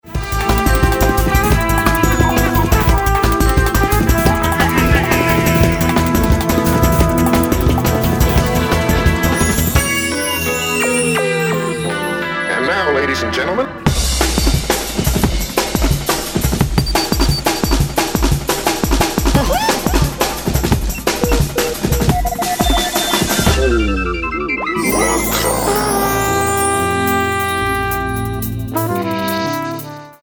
guitar: Agostin Z24 Art